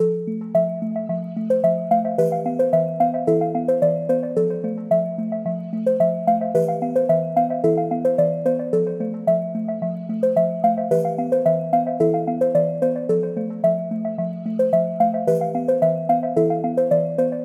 循环播放Lil Yachty类型的合成器
描述：第二部分的一些合成器线索和马林巴琴...快乐的氛围
Tag: 115 bpm Hip Hop Loops Synth Loops 2.81 MB wav Key : A Reason